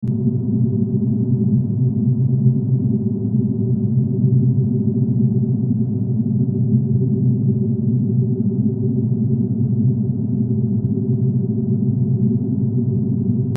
دانلود آهنگ آب 53 از افکت صوتی طبیعت و محیط
جلوه های صوتی
دانلود صدای آب 53 از ساعد نیوز با لینک مستقیم و کیفیت بالا